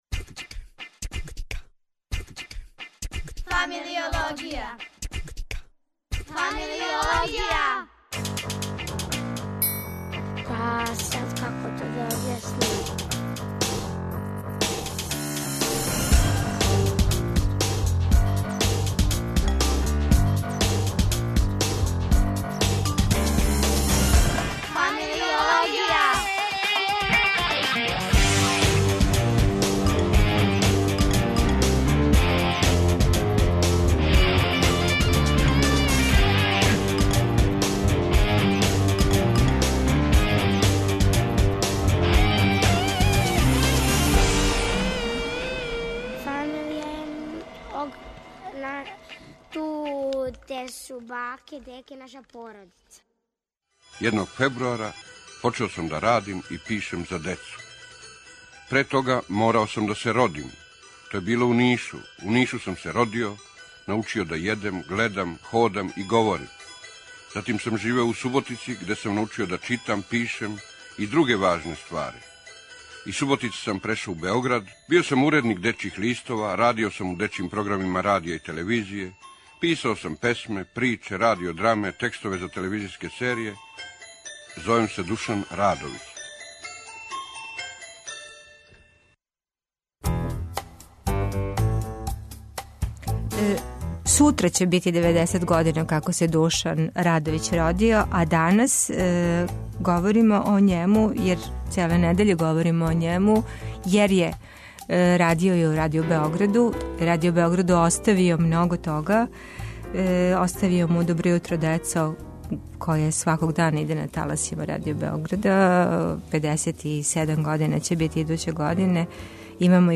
Гост у студију